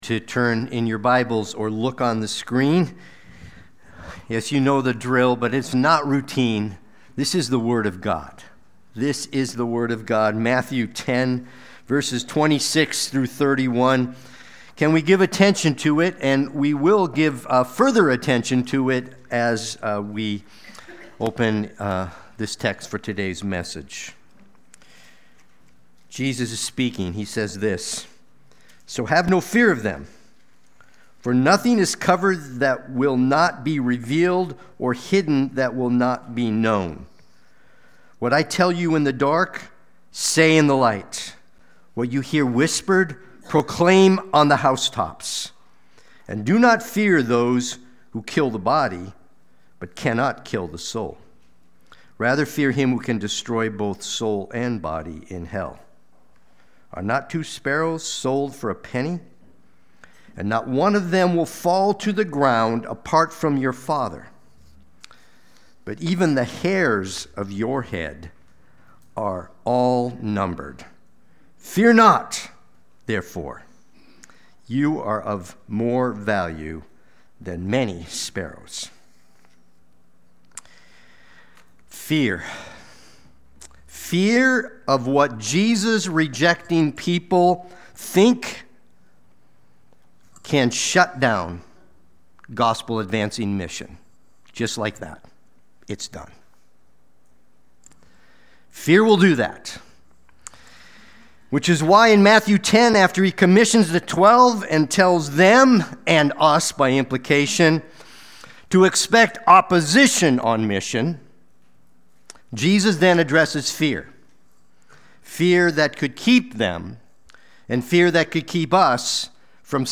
Sunday-Worship-main-51125.mp3